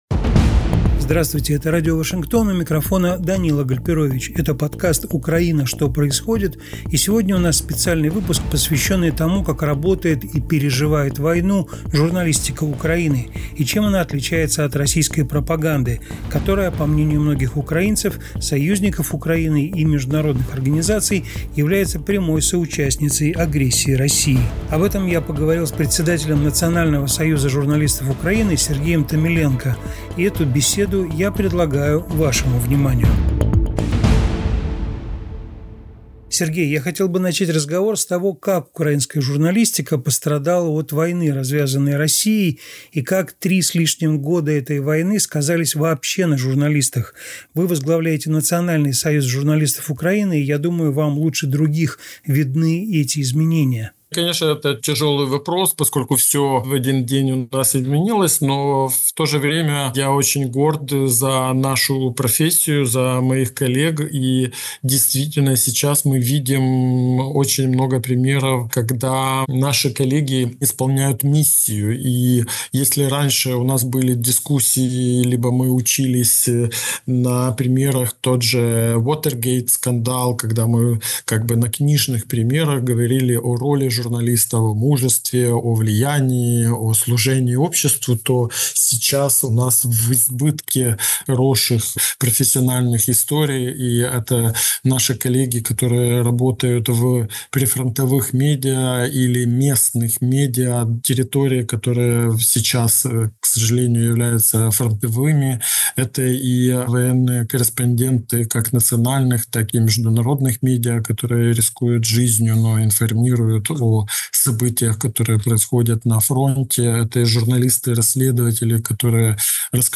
интервью